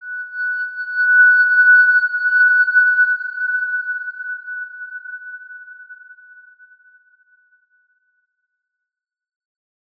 X_Windwistle-F#5-ff.wav